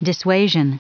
Prononciation du mot dissuasion en anglais (fichier audio)
Entrez un mot en anglais, et nous le prononcerons pour vous.